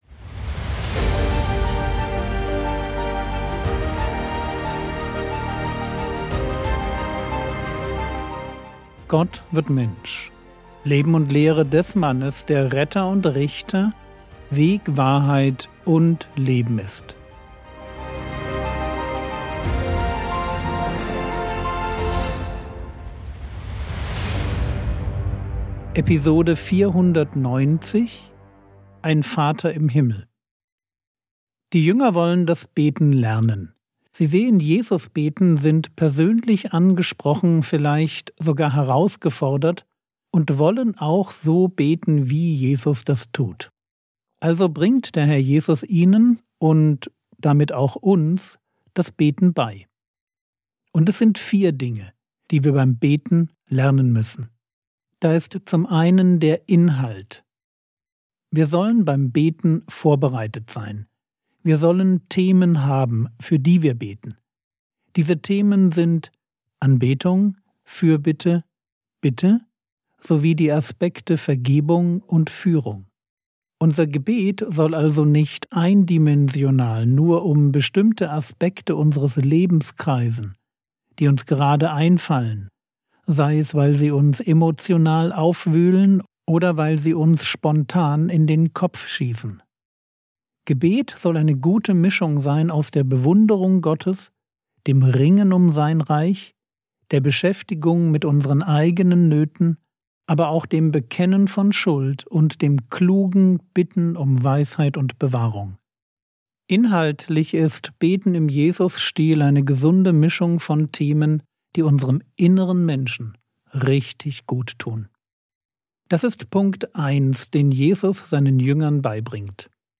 Frogwords Mini-Predigt